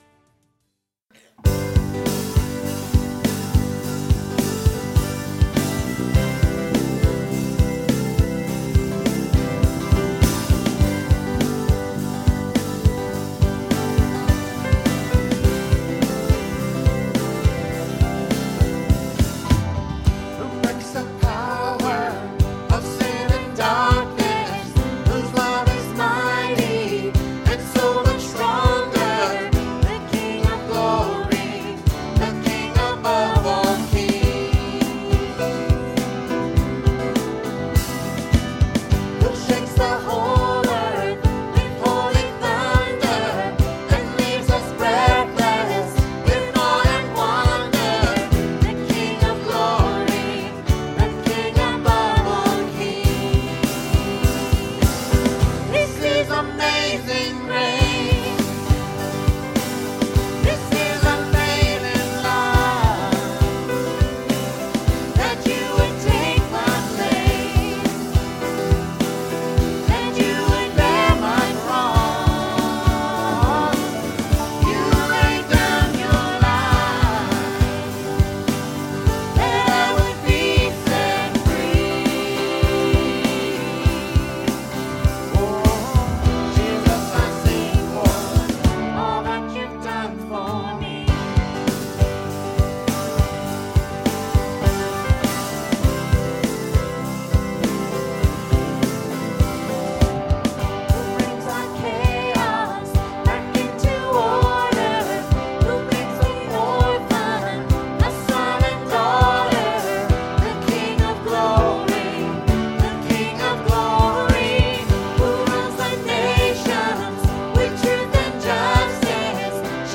3/8/15 Sermon – Churches in Irvine, CA – Pacific Church of Irvine